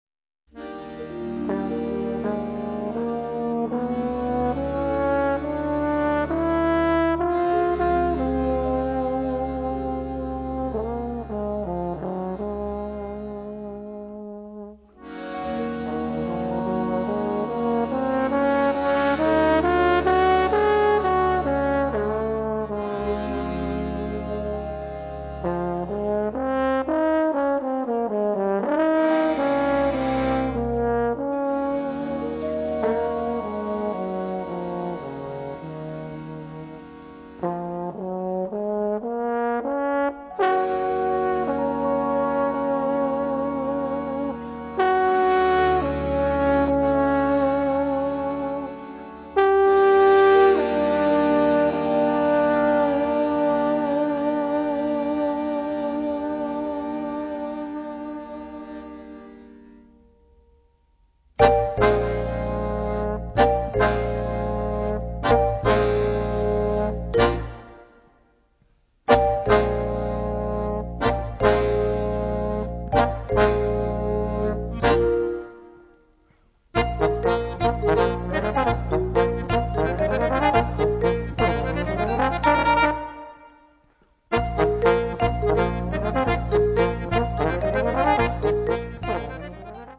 Auditorium di S. Cecilia - Perugia